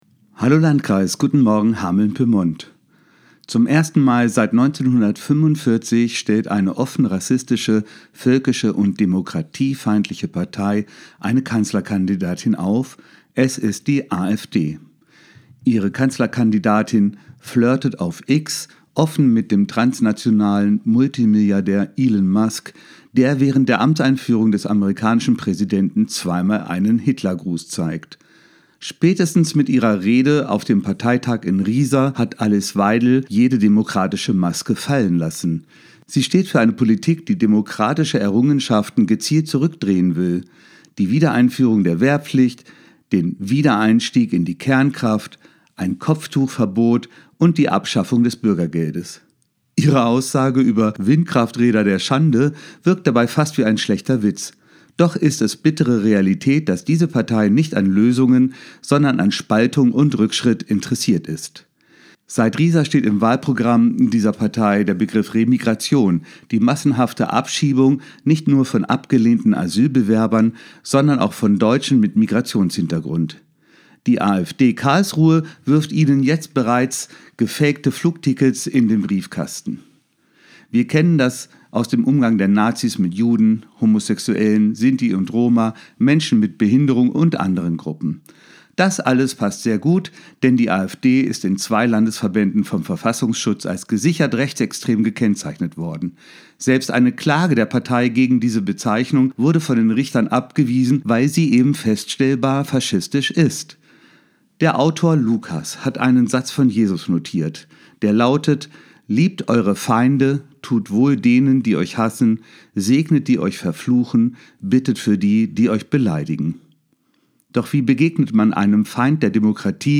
Radioandacht vom 27. Januar